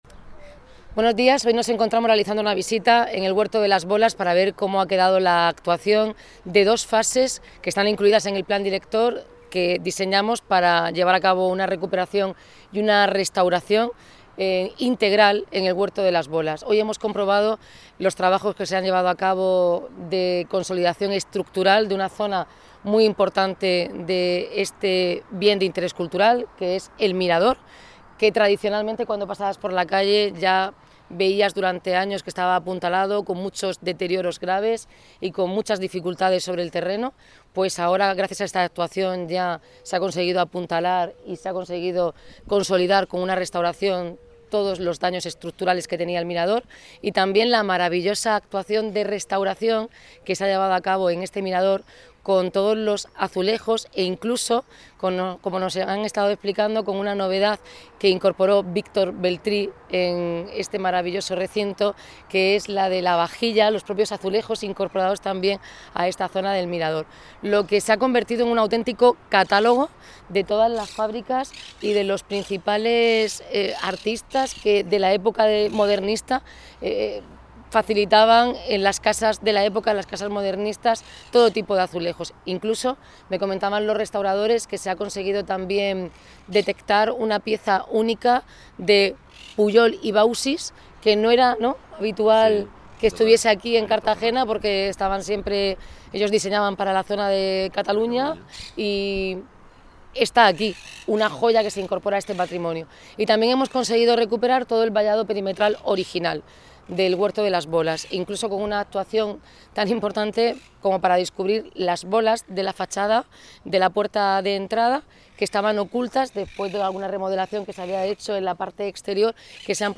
Enlace a Declaraciones de Noelia Arroyo